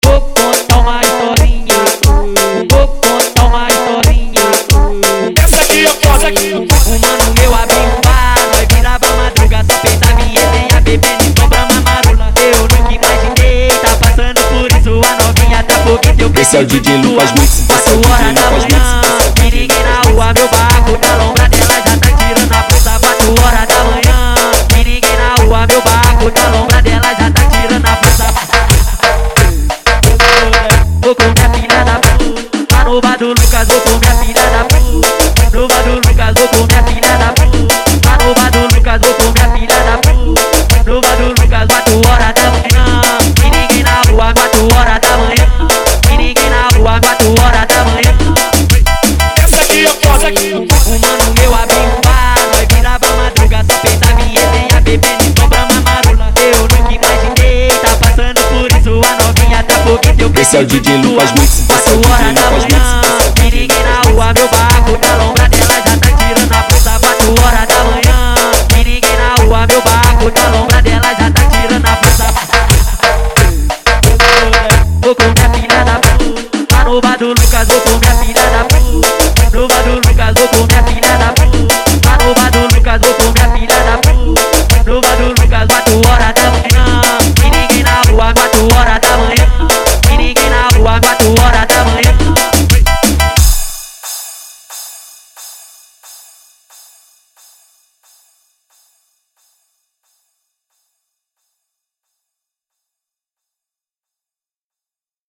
Tecno Melody